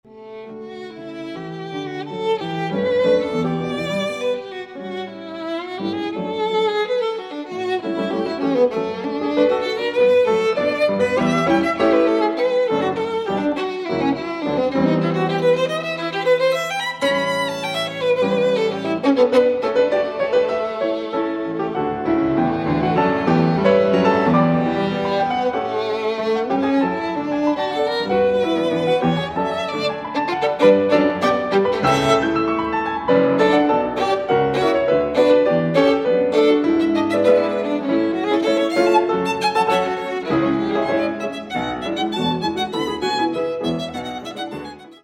piano
Allegro con moto (7:53)